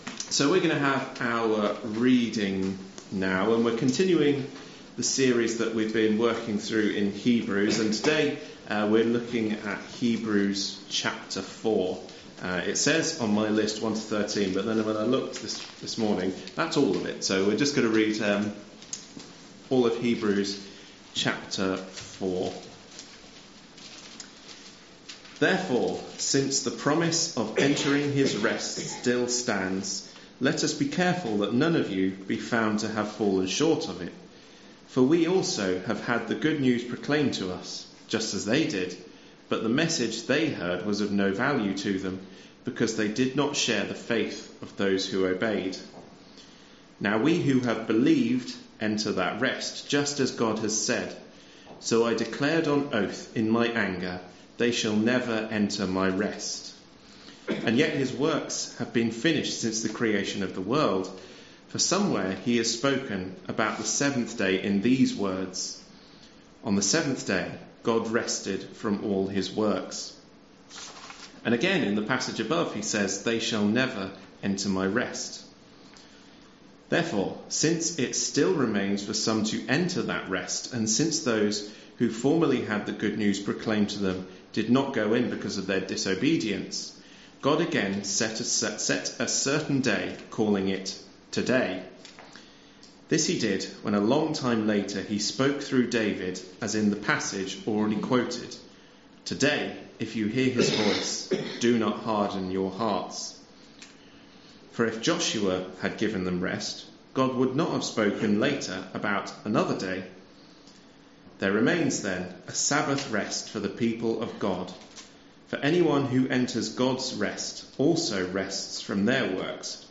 lead our sung worship